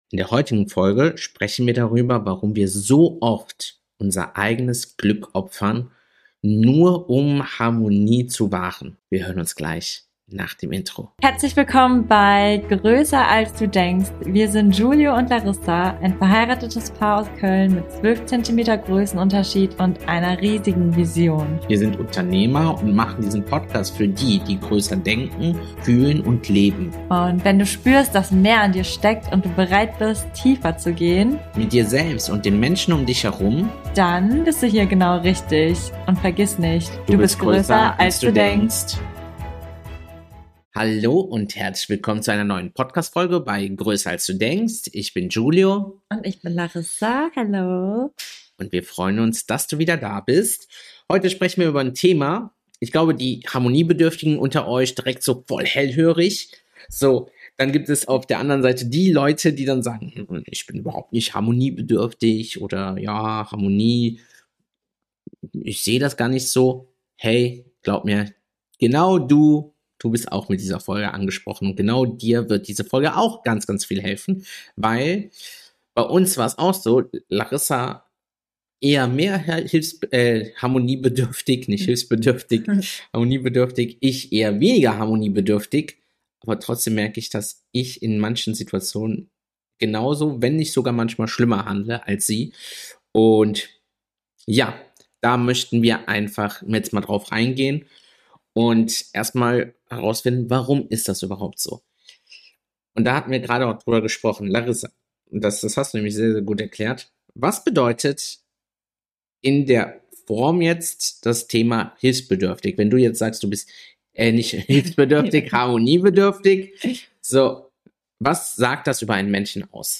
Diese Folge ist Deep Talk pur – ehrlich, roh und transformierend.